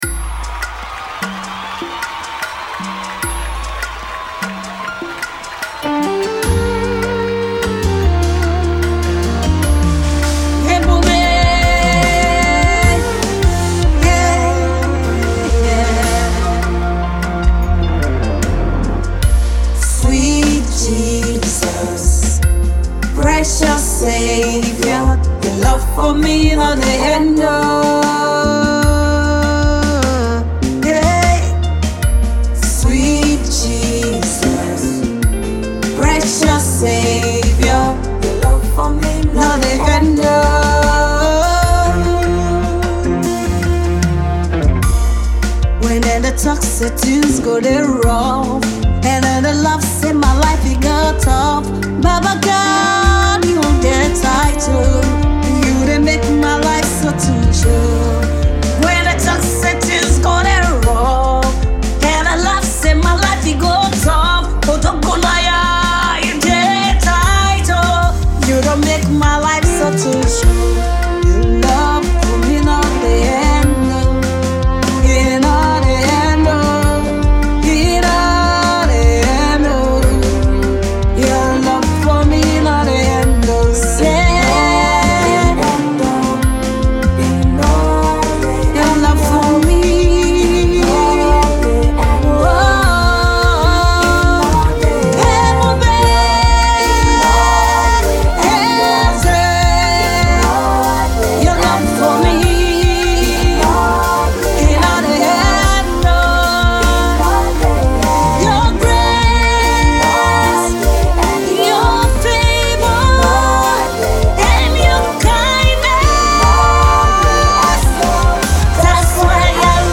Nigerian dynamic gospel songstress and exquisite songwriter